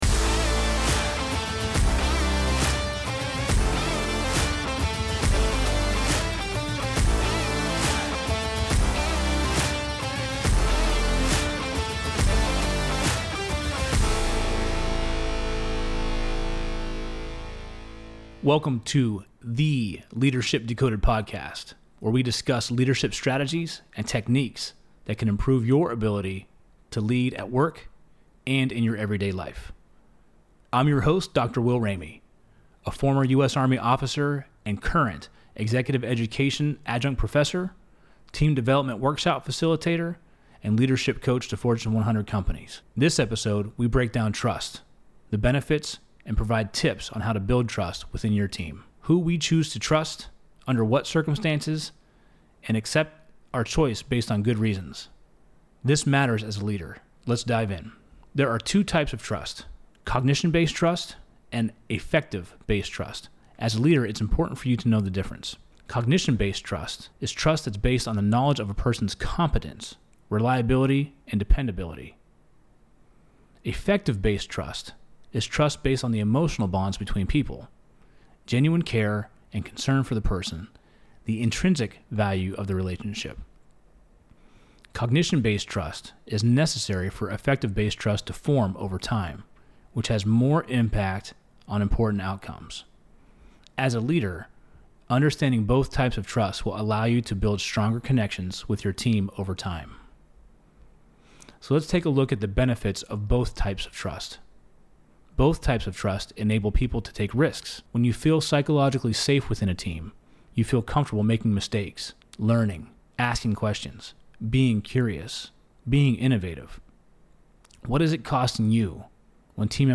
Welcome to Ep.005 of the Leadership Decoded Podcast in the Loop Internet studio